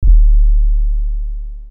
Rollie808_YC.wav